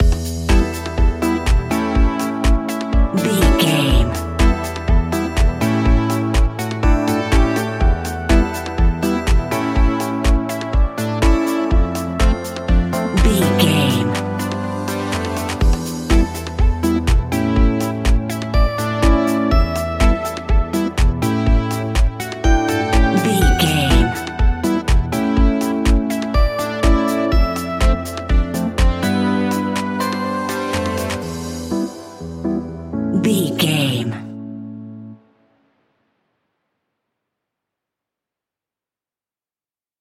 Aeolian/Minor
uplifting
energetic
bouncy
synthesiser
drum machine
electric piano
funky house
deep house
nu disco
groovy
funky guitar
synth bass